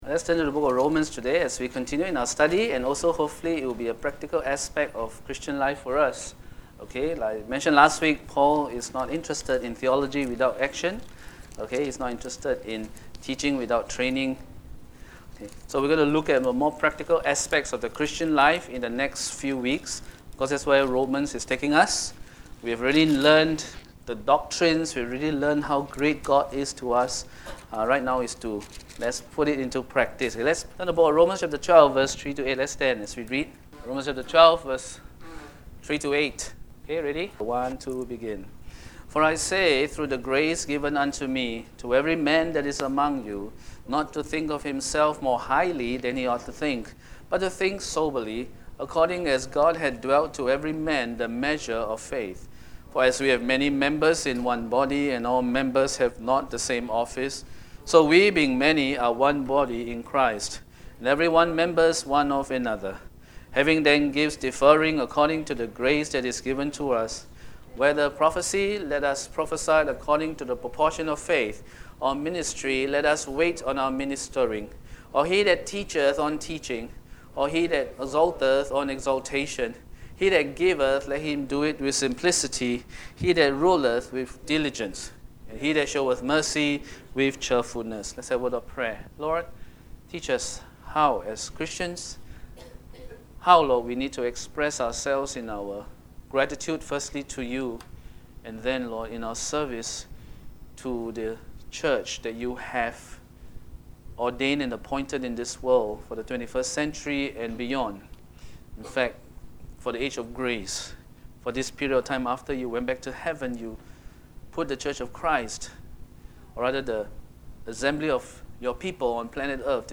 Worship Service